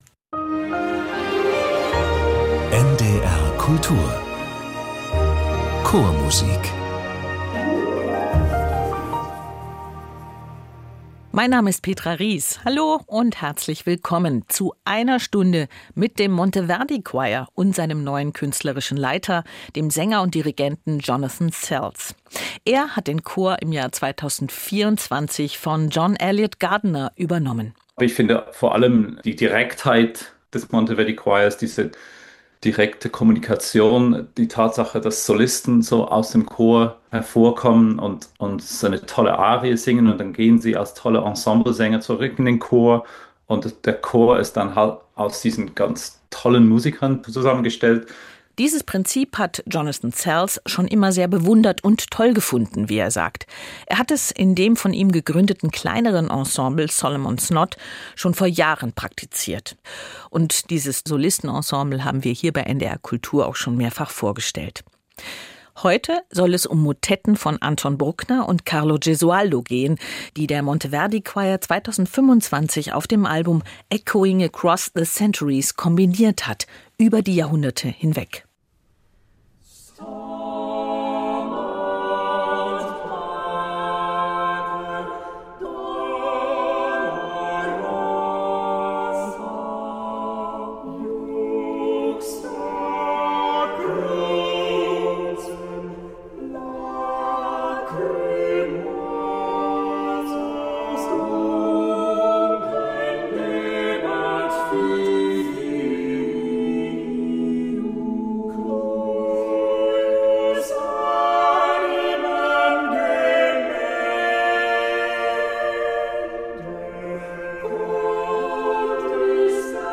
Motetten